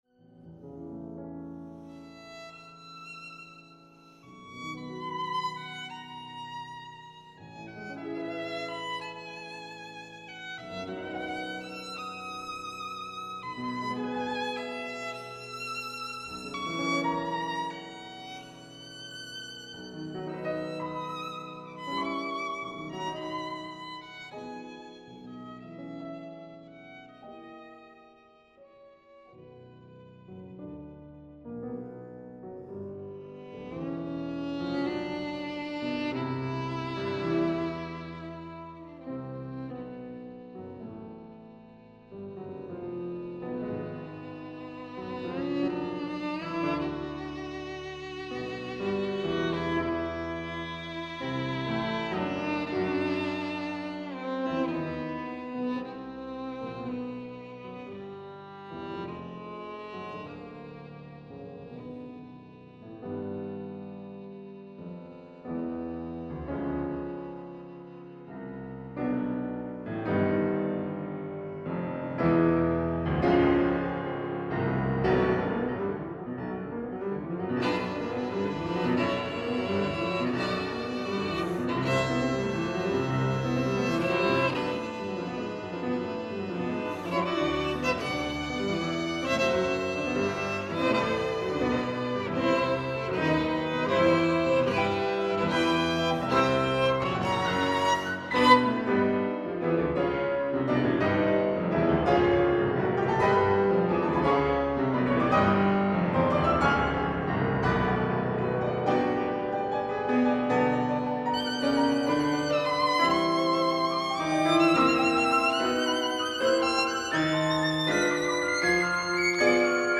Sonata for violin and piano